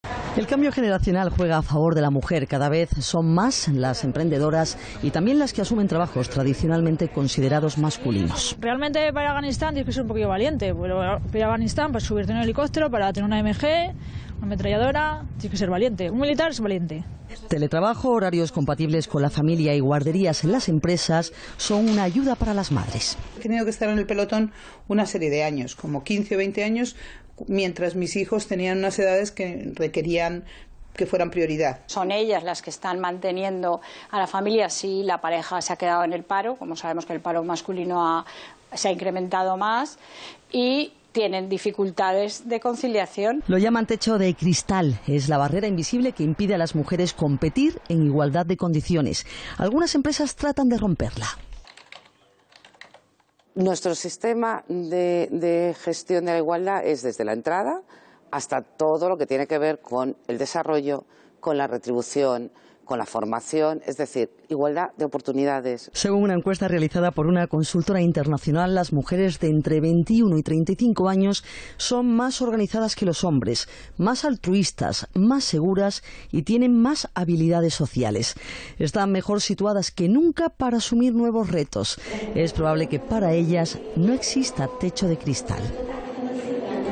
EL TECHO DE CRISTAL Algunos avances y algunas esperanzas en este reportaje.